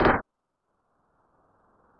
mnl-medium noise 1.wav